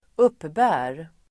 Ladda ner uttalet
uppbära verb, collect , draw Grammatikkommentar: A & x Uttal: [²'up:bä:r] Böjningar: uppbar, uppburit, uppbär, uppbära, uppbär Definition: regelbundet ta emot Exempel: han uppbär en hög lön (he draws a large salary)